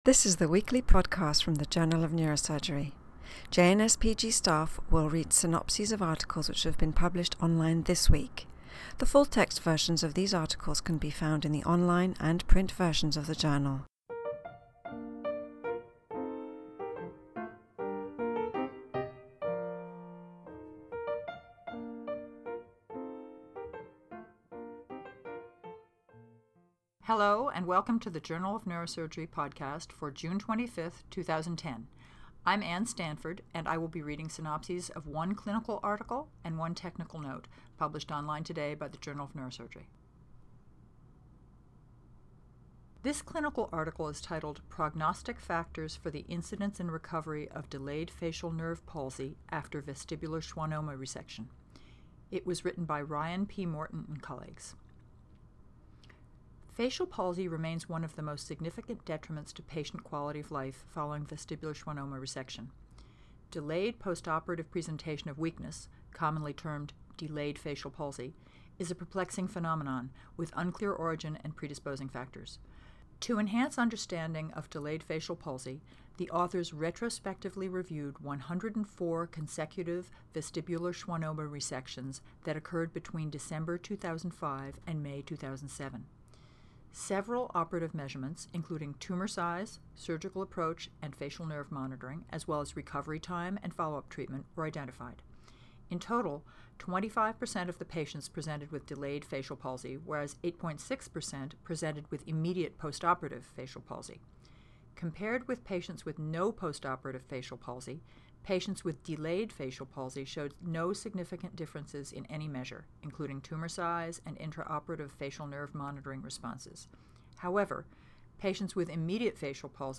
reads synopses of Journal of Neurosurgery articles published online on June 25, 2010.